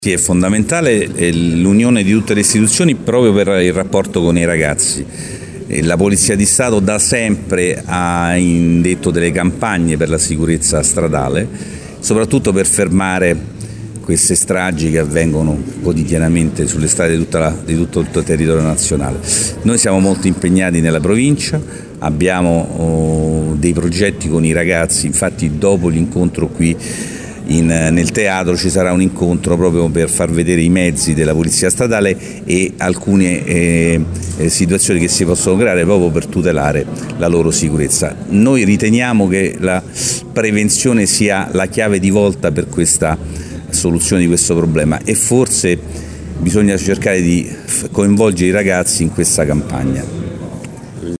Presente al D’Annunzio il questore Fausto Vinci che ha anche illustrato le attività che saranno svolte in piazza della Libertà con la polizia stradale ricordando l’impegno sul fronte della sicurezza stradale e ha rimarcato l’importanza dell’impegno comune tra istituzioni.